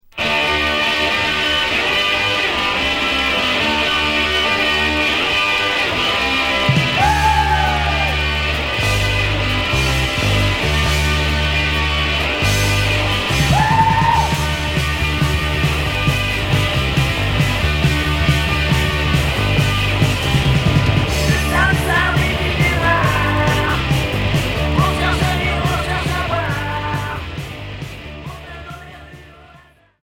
Rock Premier 45t retour à l'accueil